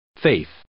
Click any 'English' word, and you will hear how it is pronounced.